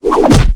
bash2.ogg